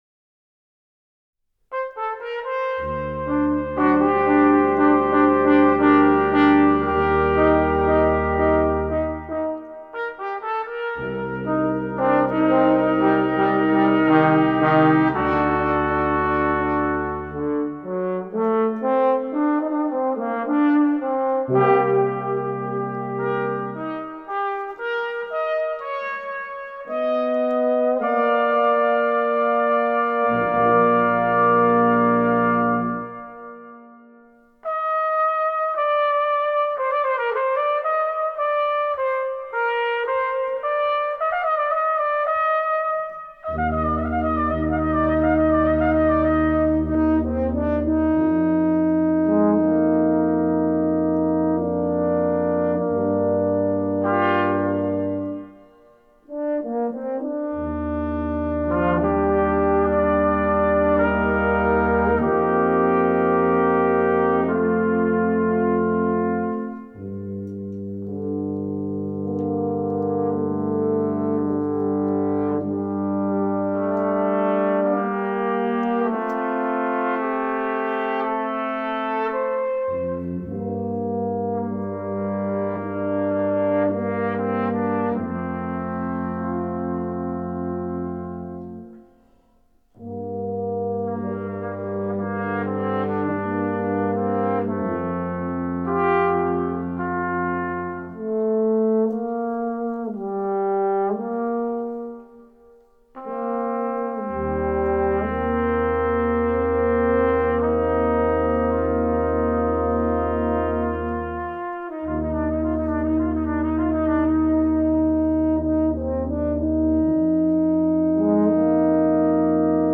Adagio Sib 4   (2007) Full scoreTrumpet1, Trumpet2, Horn, Tenor Trombone & Bass Trombone.   Midi-rendered.